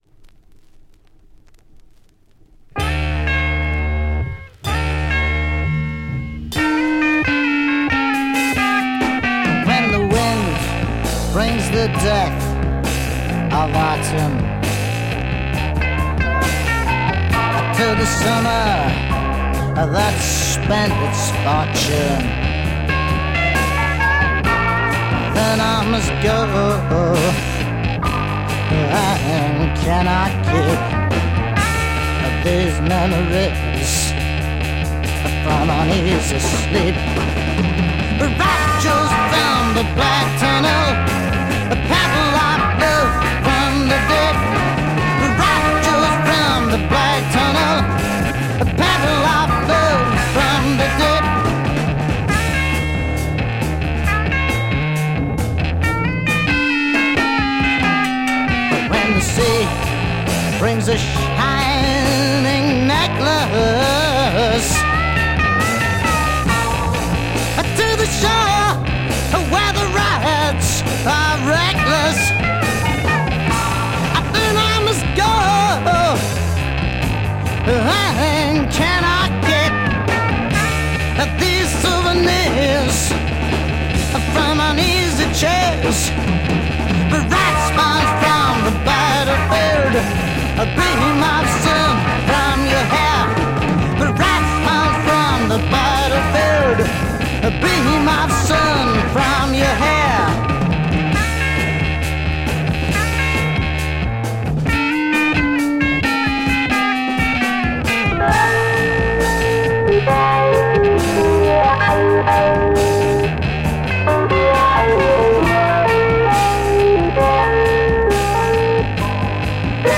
Hard soul rock